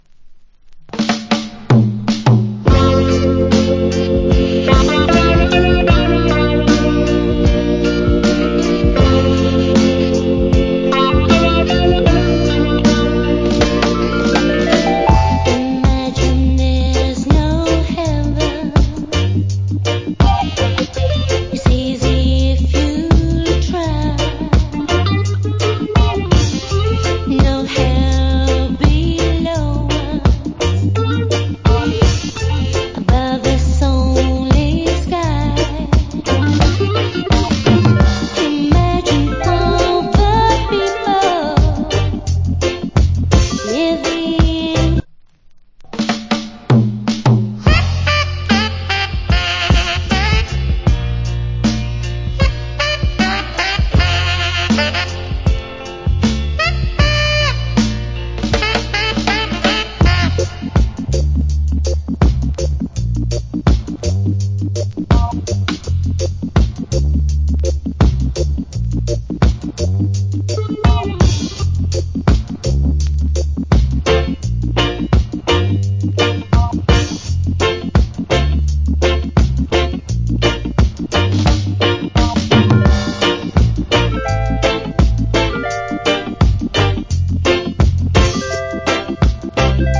Cool UK Lovers.